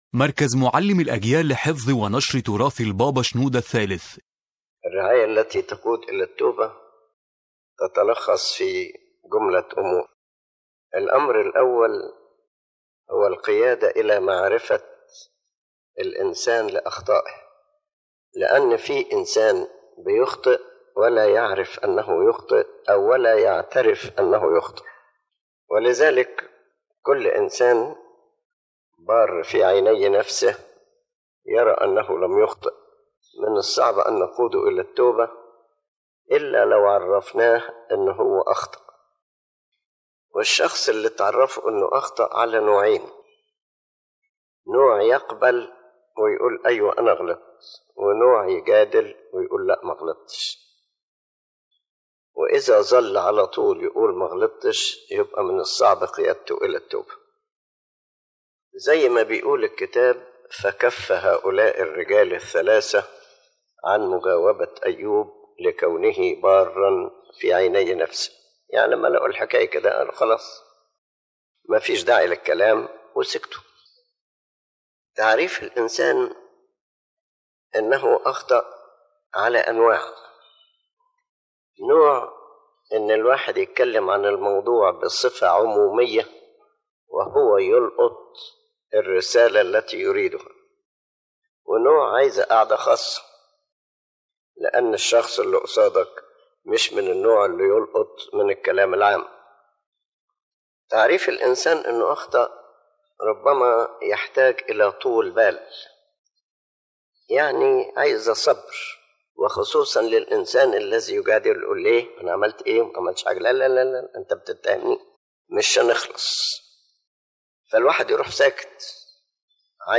⬇ تحميل المحاضرة أولًا: معرفة الإنسان بخطيته تؤكد المحاضرة أن أول خطوة في الرعاية التي تقود إلى التوبة هي أن يعرف الإنسان أنه قد أخطأ.